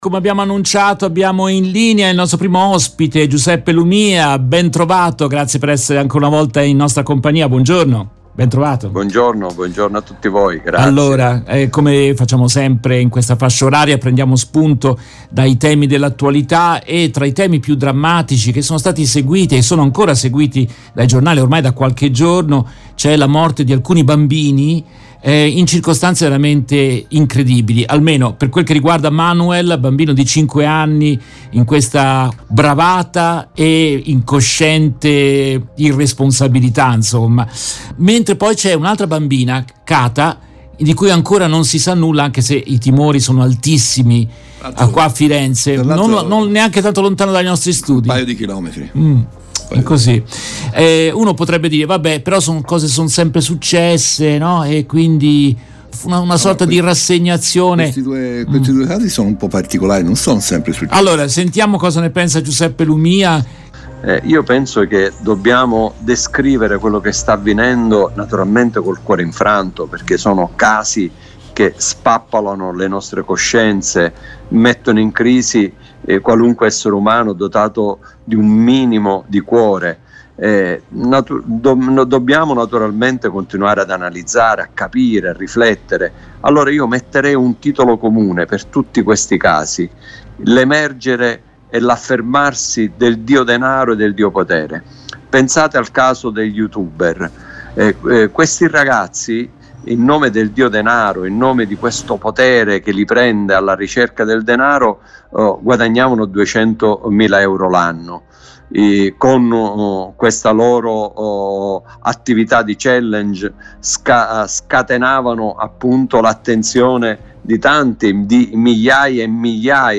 Nel corso della trasmissione in diretta del 19 giugno 2023
intervistano per RVS Giuseppe Lumia, già Deputato e Senatore della Repubblica.